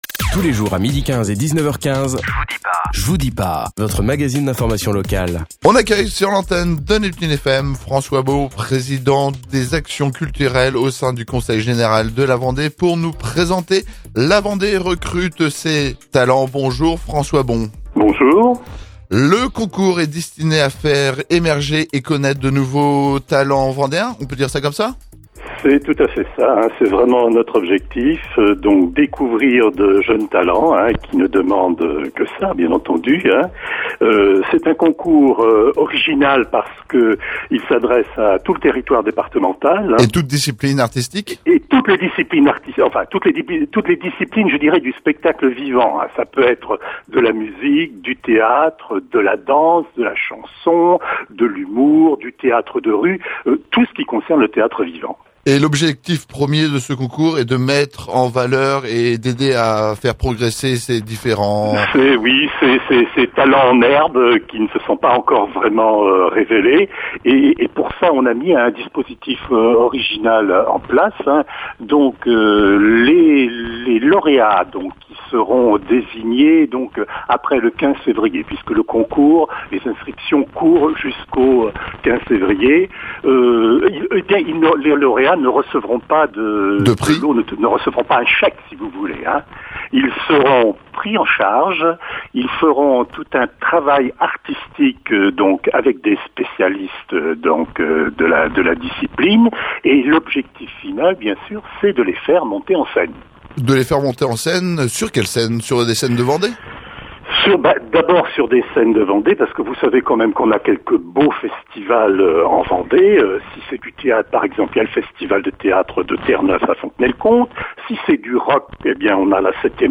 François Bon, Président de la commission éducation, culture, sport et relations internationales au conseil général de la Vendée, nous présente…
Interview